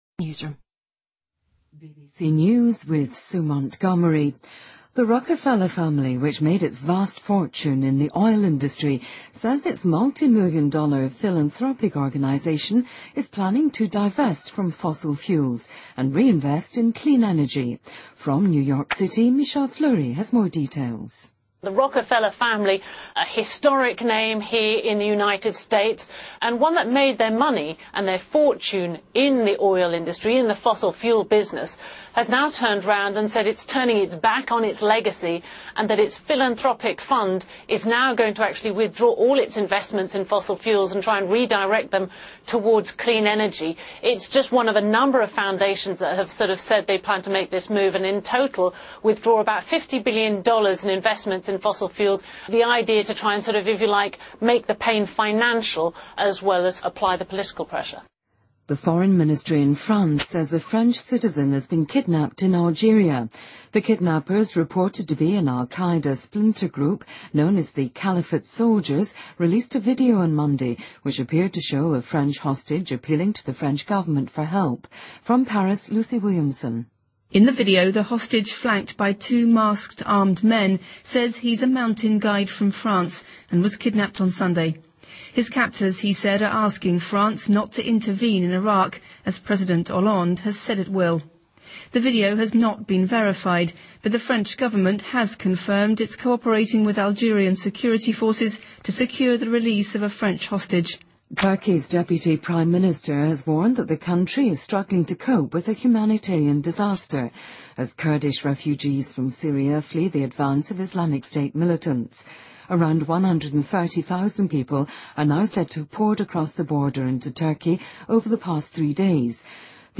BBC news,洛克菲勒家族将投资于清洁能源